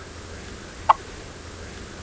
Whip-poor-will Caprimulgus vociferus
Flight call description No known flight call but sometimes gives a soft "puk" or muttering series of "puk" notes in short flights.
Single call from a series from a bird in short flight.
Similar species Shorter than Chuck-wills-widow's "aunk" call.